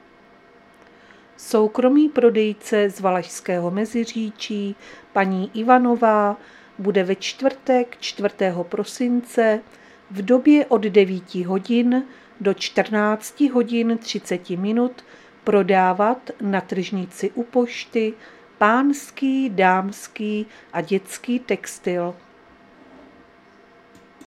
Záznam hlášení místního rozhlasu 3.12.2025